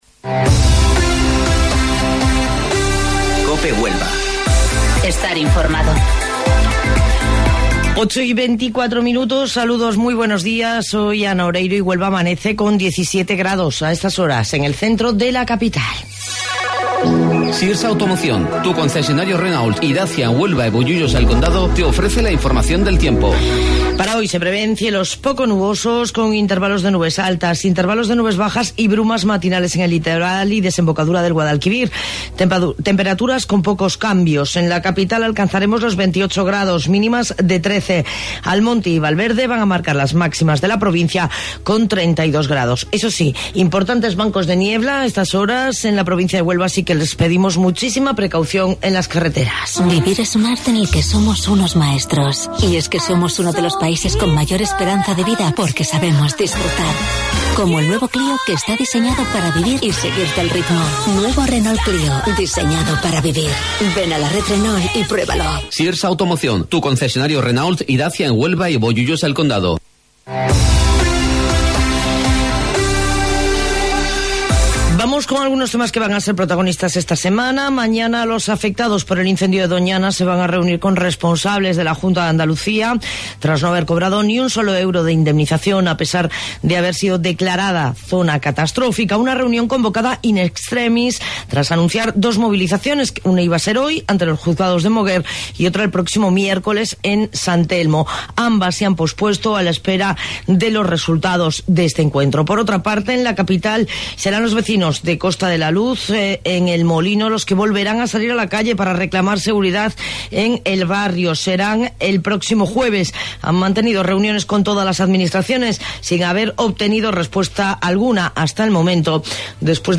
AUDIO: Informativo Local 08:25 del 30 de Septiembre